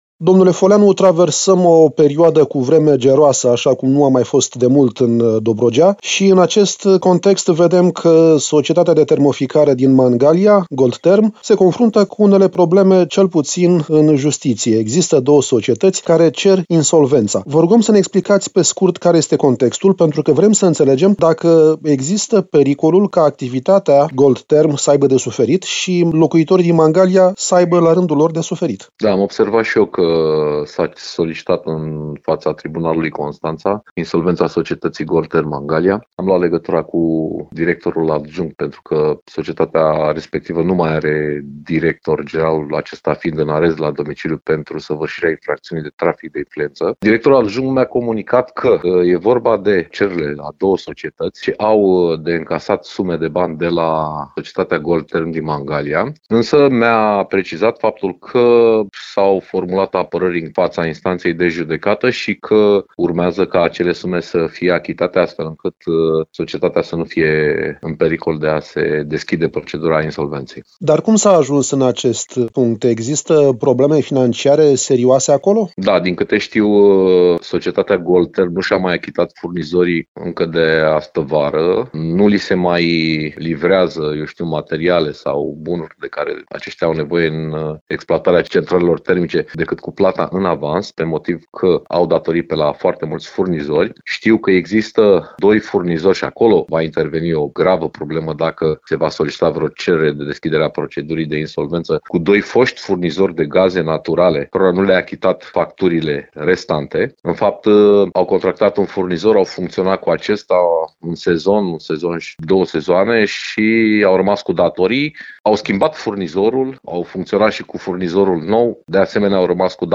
Viceprimarul Paul Foleanu, despre riscul ca locuitorii municipiului să rămână fără apă caldă și căldură.
în interviul cu viceprimarul municipiului Mangalia